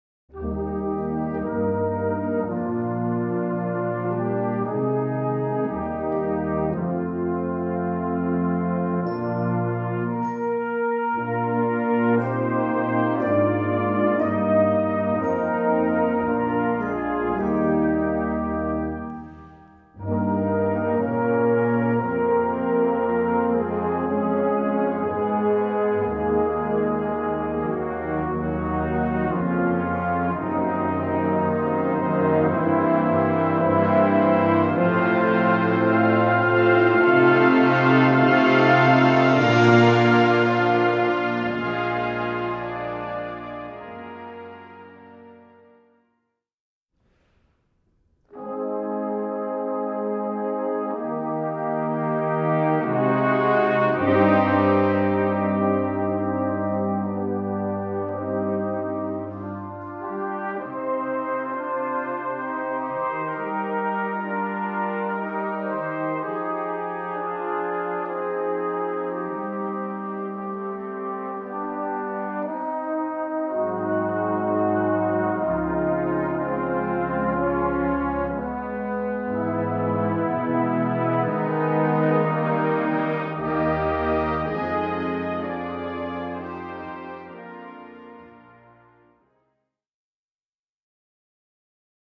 2:25 Minuten Besetzung: Blasorchester PDF